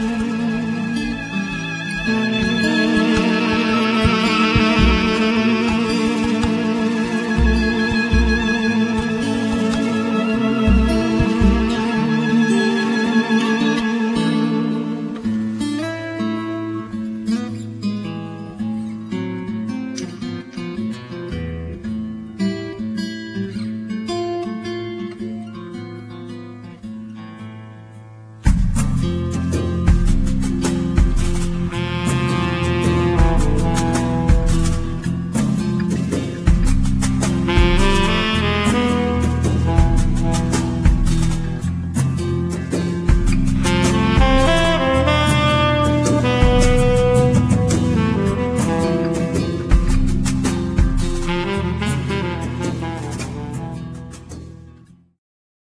skrzypce
saksofon tenorowy, klarnet
gitara akustyczna i elektryczna
bębny, perkusja
gitara basowa i elektryczna